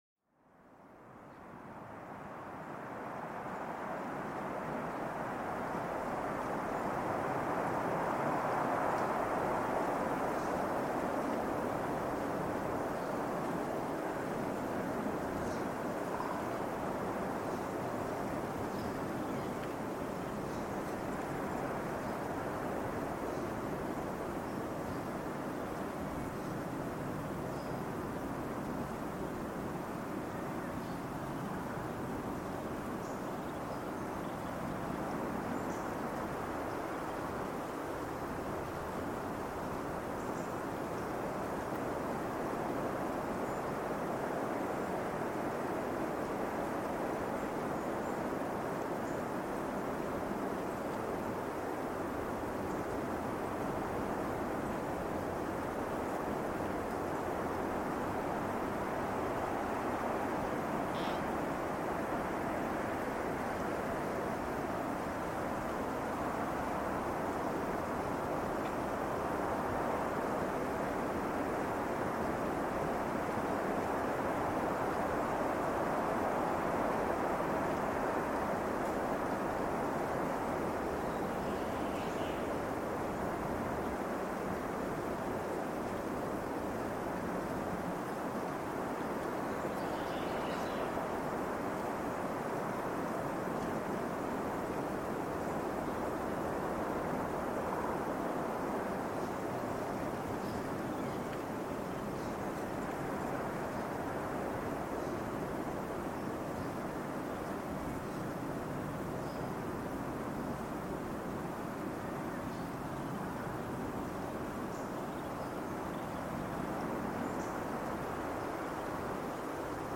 Susurros del Viento: Relajación y Paz con los Sonidos del Bosque